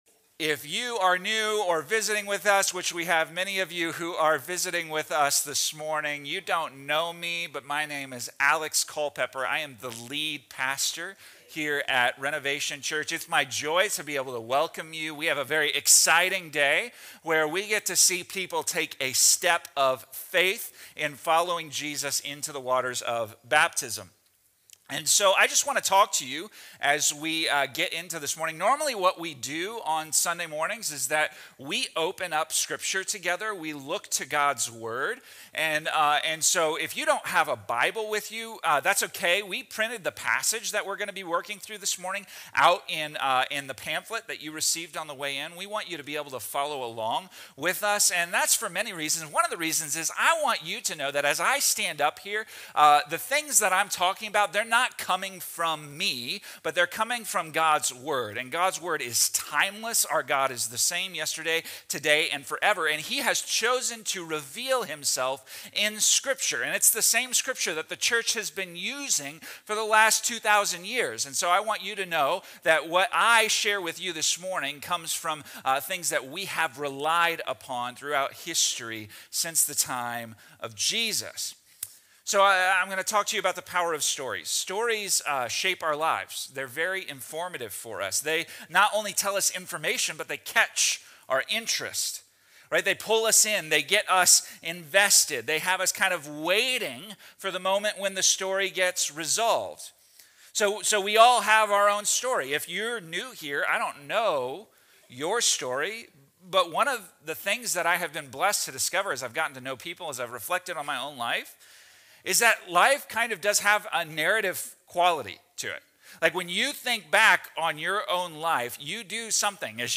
This baptism service from Acts 22:1–16 centers on the transforming power of encountering Jesus. Paul’s story is shared as an example of how God can take a life marked by opposition and sin and rewrite it with grace, forgiveness, and purpose. Baptism is presented as both an act of obedience and a public declaration that life now belongs to Christ.